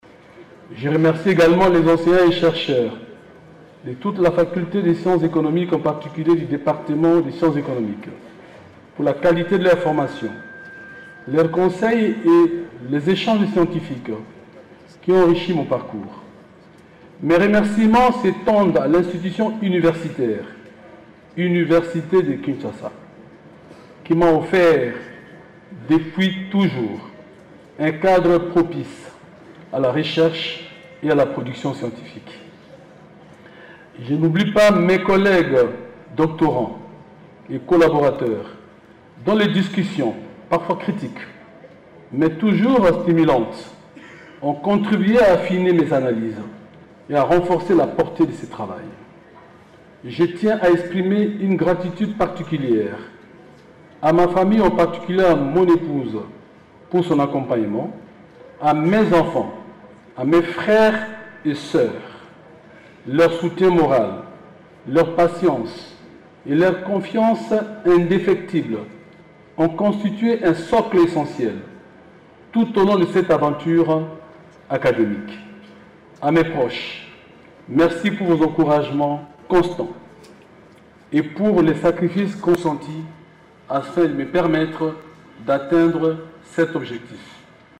discour_docteur_bussa.mp4_.mp3_2-web_0.mp3